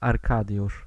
Arkadiusz (Polish: [arˈka.djuʂ] ⓘ) is a masculine Polish given name.
Pl-Arkadiusz.ogg.mp3